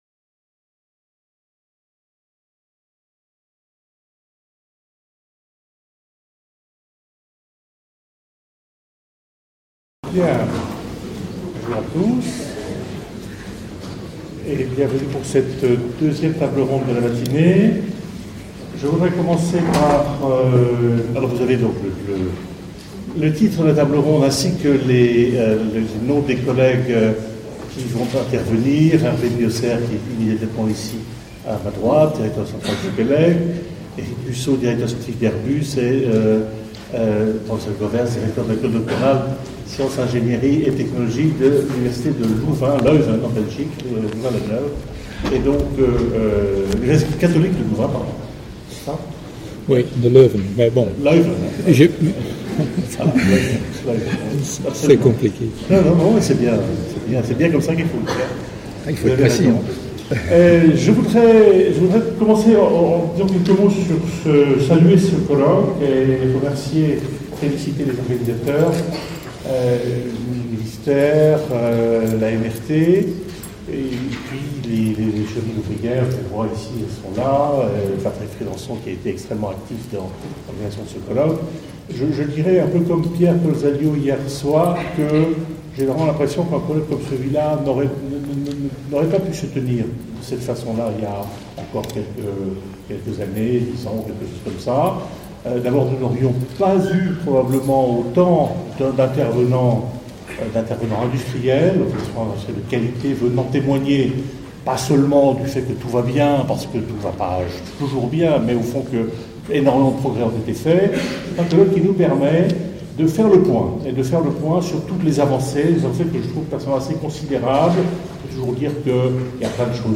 Table-ronde